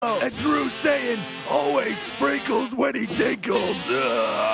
sprkile tinkle